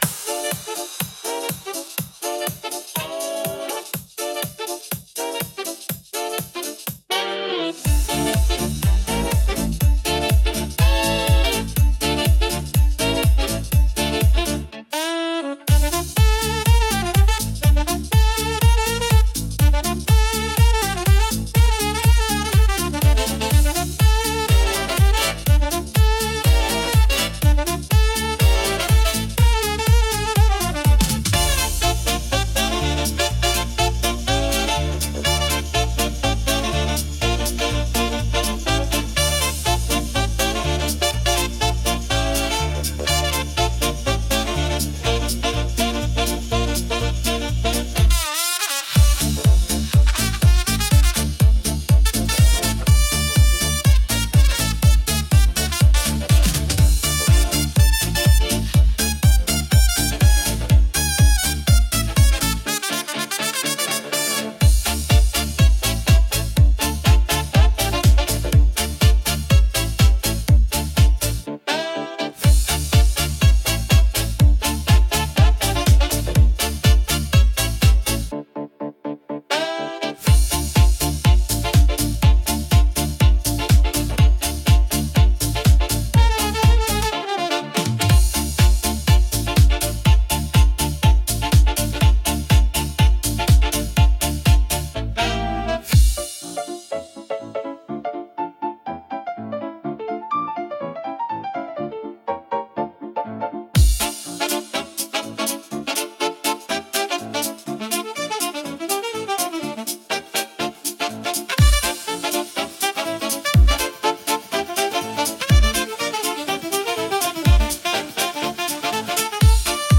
強力なブラスと推進力のあるドラムが響く、アップビートでパワフルなエレクトロスウィング。
パンチの効いたリズムとエネルギッシュなサウンドは、聴くだけで身体が動き出すような「ノリノリ」な一曲です。